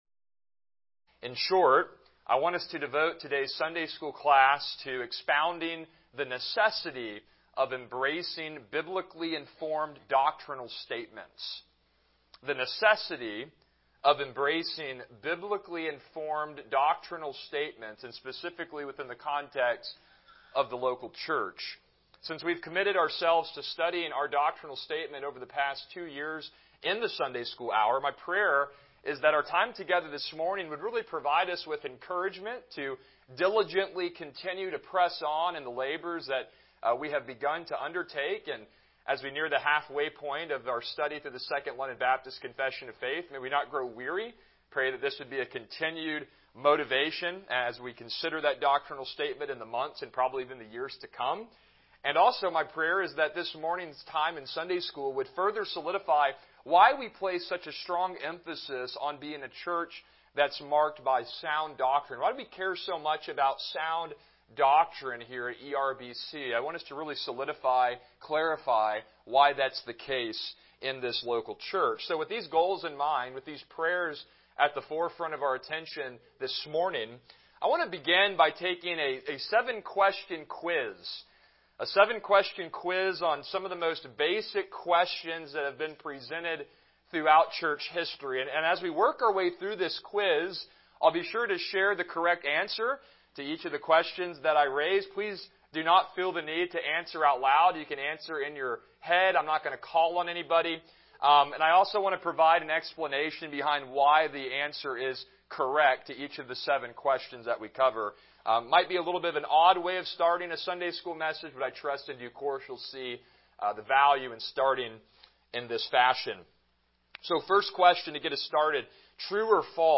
Ecclesiological Distinctives of ERBC Service Type: Sunday School « How Will You Respond to Christ’s Sacrificial Death?